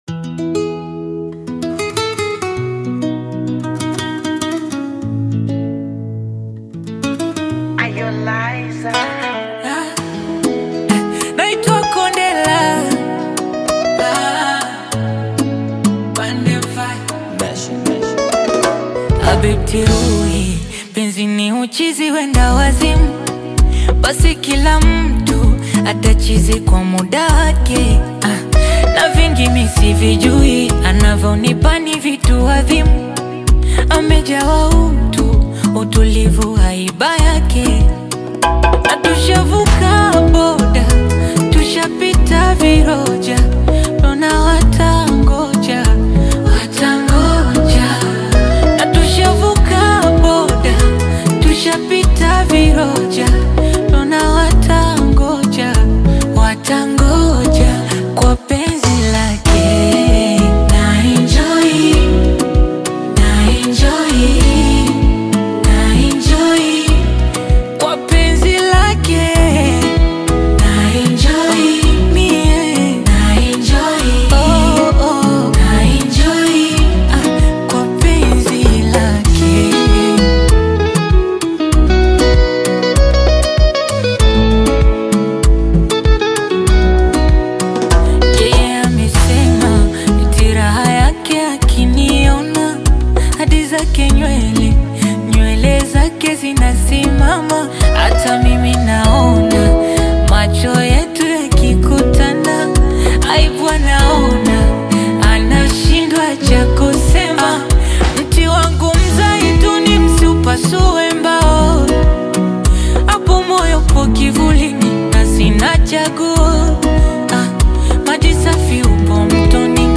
Swahili love song
heartfelt vocals